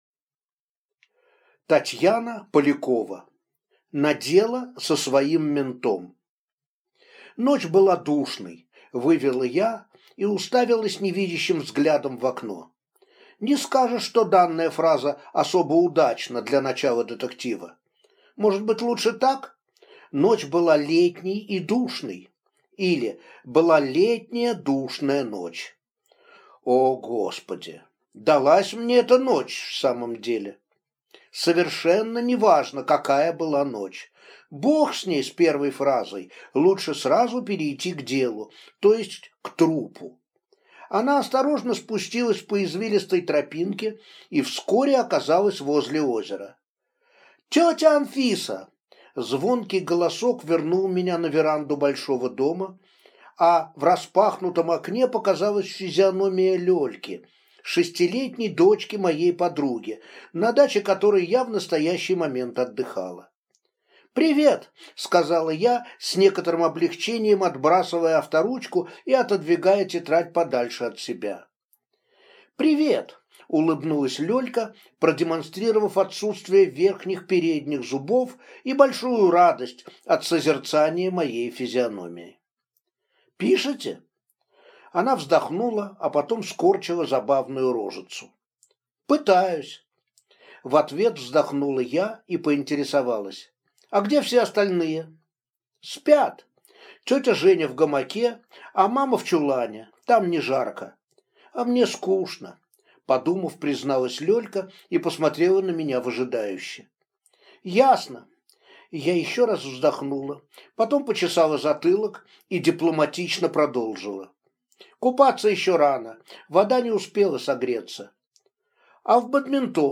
Аудиокнига На дело со своим ментом | Библиотека аудиокниг